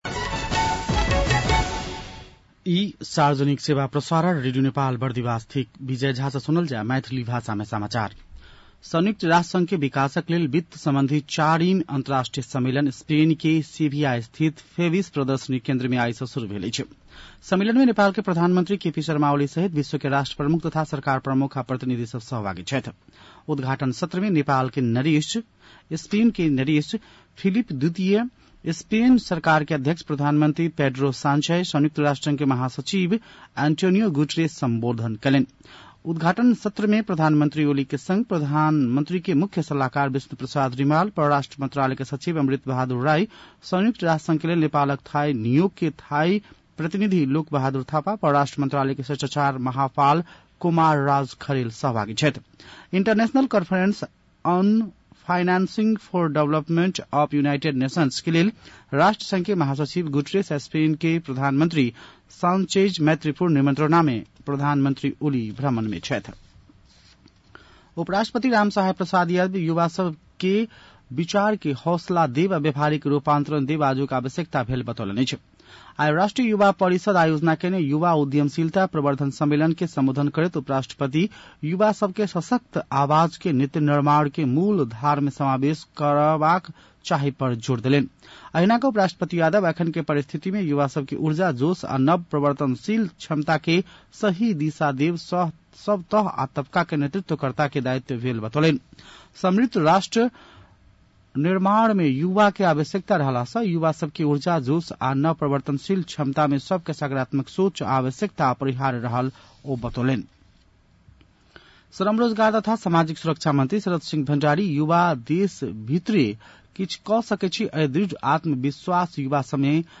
मैथिली भाषामा समाचार : १६ असार , २०८२